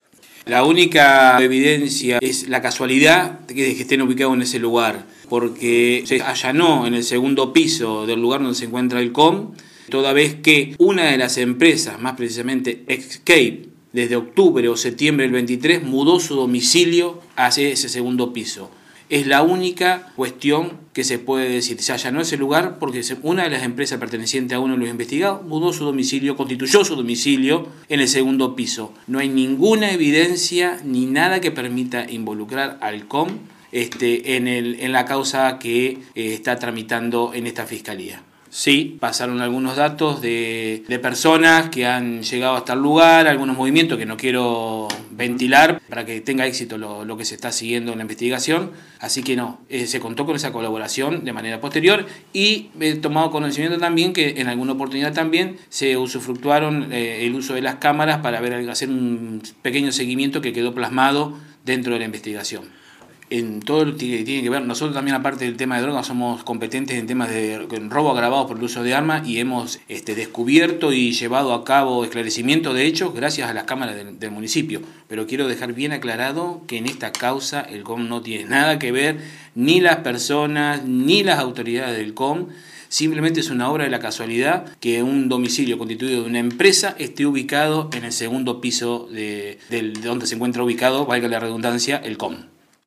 30-05-AUDIO-Fiscal-Carlos-Larrarte-COM.mp3